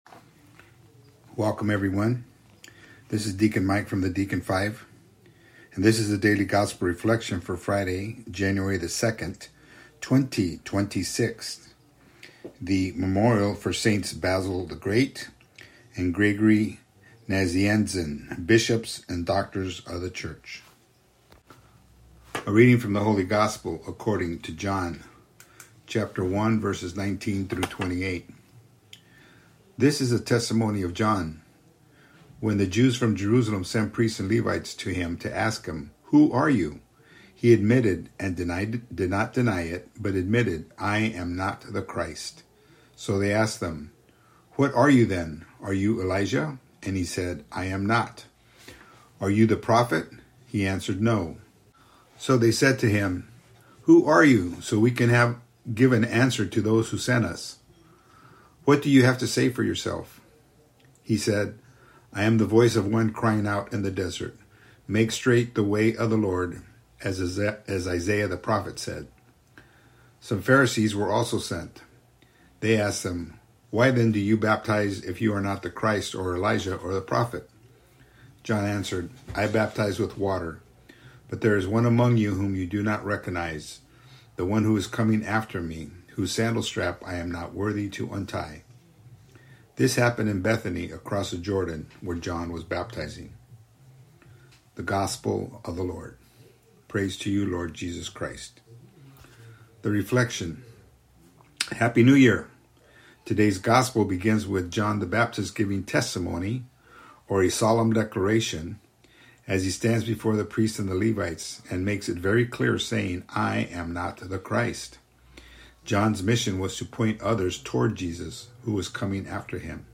Audio Reflection: